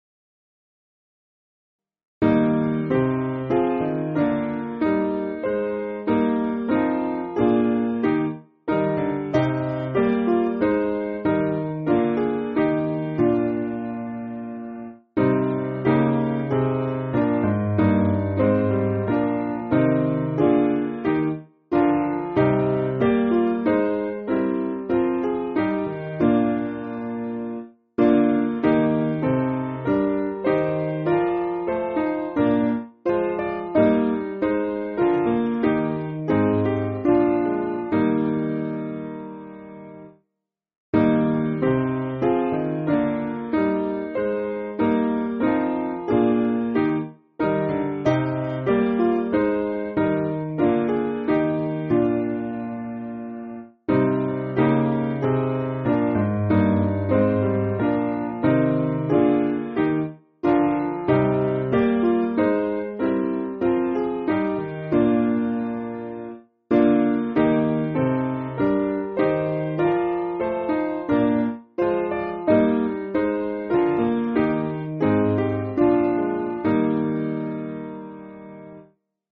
Simple Piano
(CM)   3/Eb